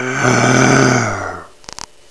***ROAR**** This is the lion.
ROAR!
lion.wav